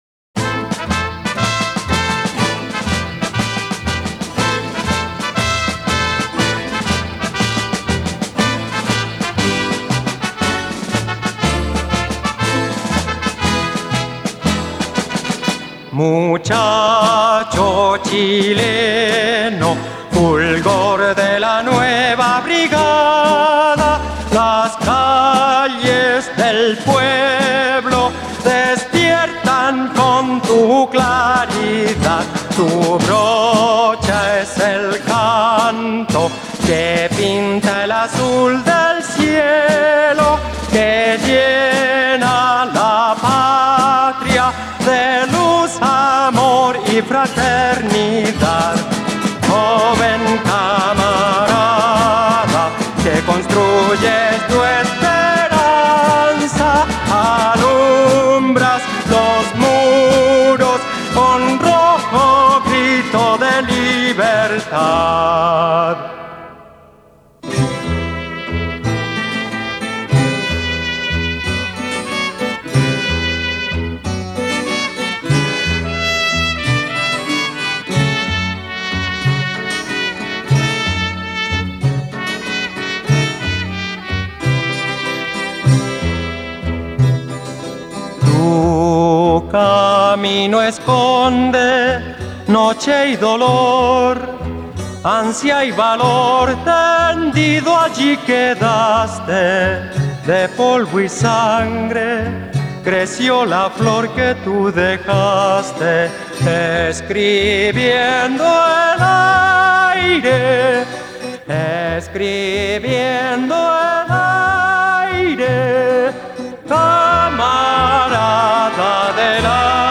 ESTILO: Cantautor